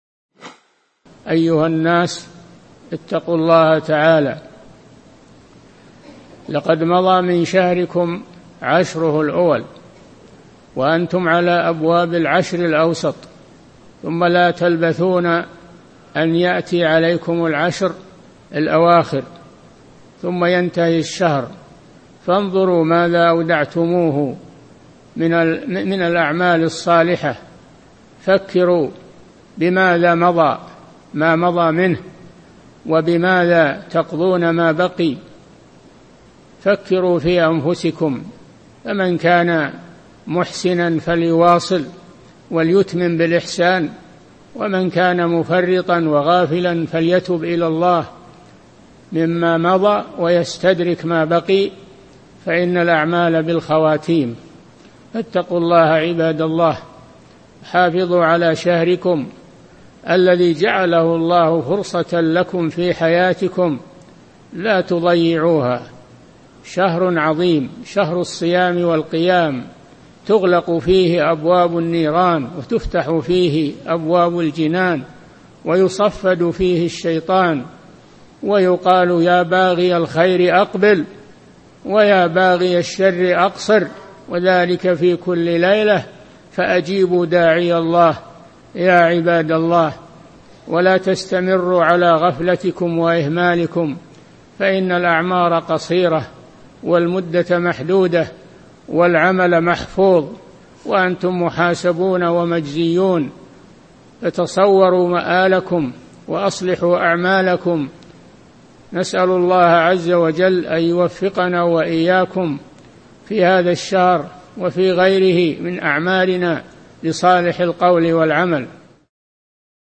القسم: من مواعظ أهل العلم
Download audio file Downloaded: 467 Played: 826 Artist: معالي الشيخ د. صالح الفوزان Title: أهمية استغلال العشر الأوسط من رمضان Album: موقع النهج الواضح Length: 2:00 minutes (546.51 KB) Format: MP3 Mono 22kHz 32Kbps (VBR)